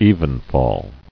[e·ven·fall]